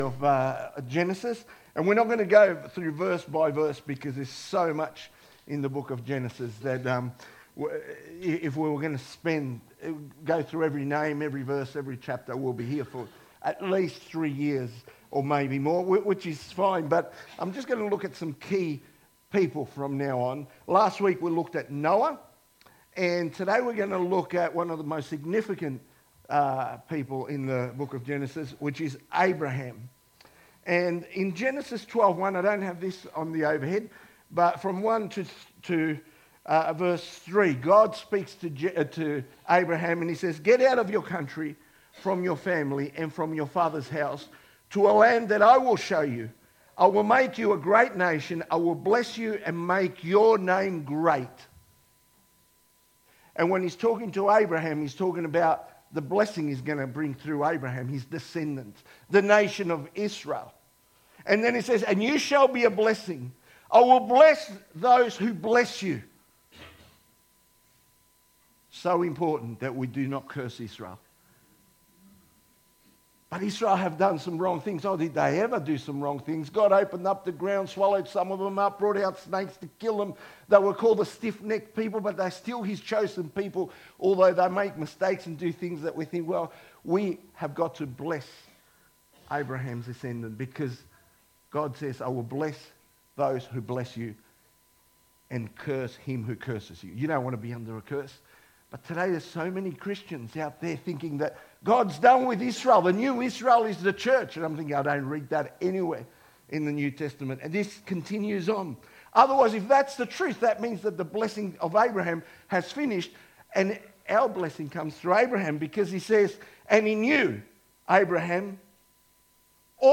2025 • 20.82 MB Listen to Sermon Download this Sermon Download this Sermon To download this sermon